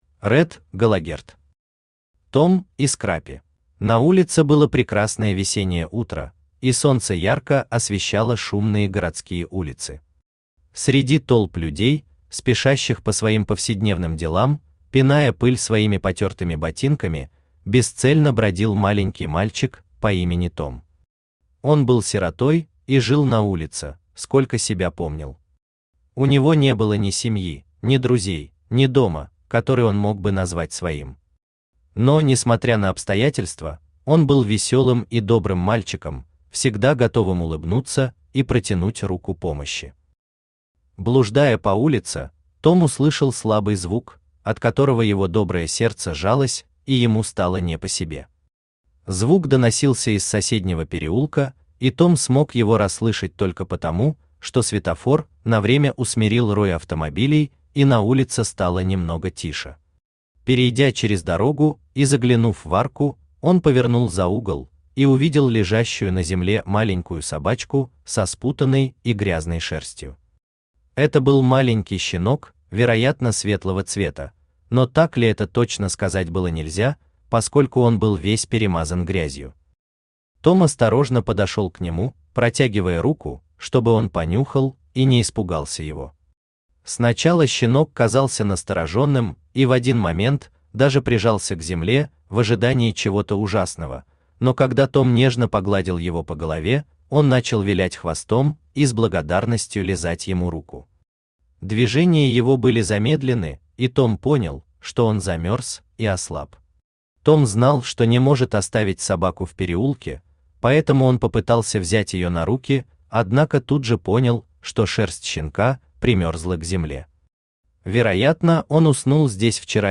Аудиокнига Том и Скраппи | Библиотека аудиокниг
Aудиокнига Том и Скраппи Автор Ред Галогерт Читает аудиокнигу Авточтец ЛитРес.